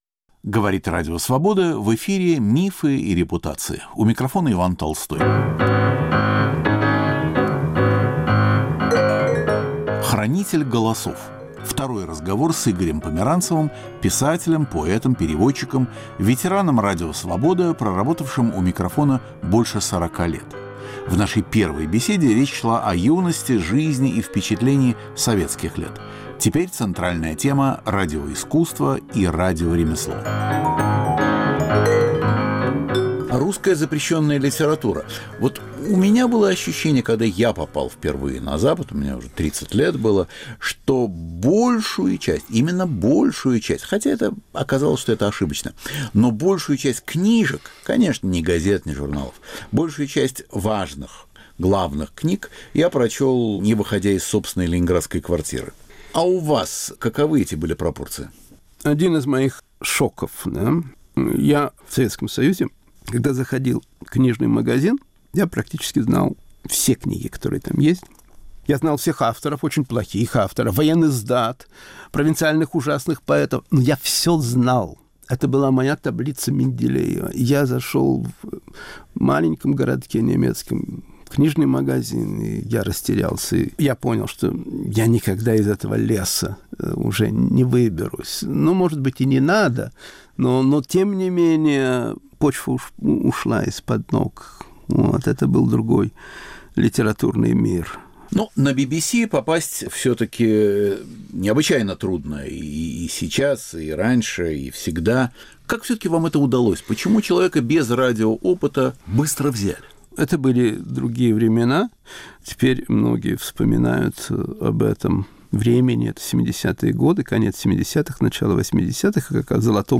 Сегодня - вторая (заключительная) беседа с Игорем Яковлевичем. Годы на Западе.